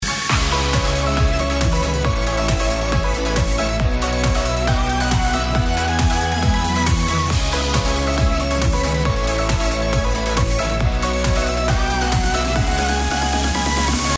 我们将选取一段游戏音乐歌曲进行播放；